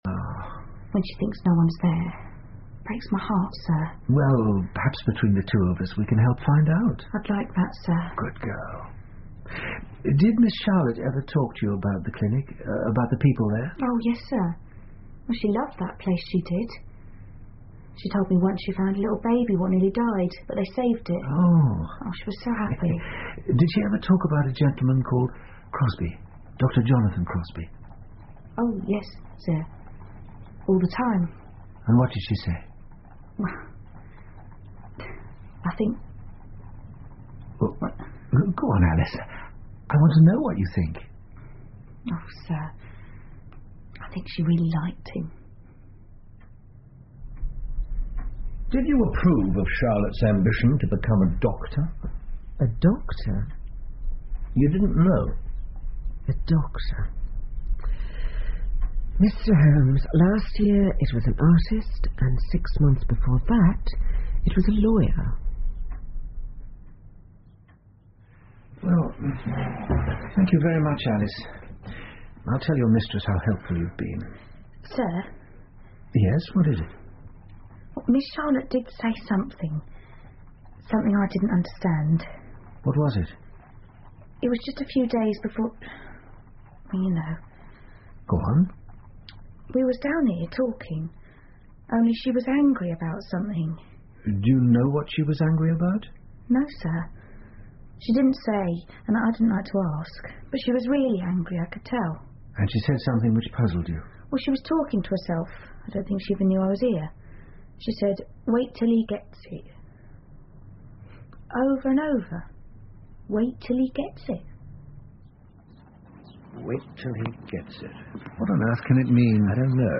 福尔摩斯广播剧 The Tragedy Of Hanbury Street 4 听力文件下载—在线英语听力室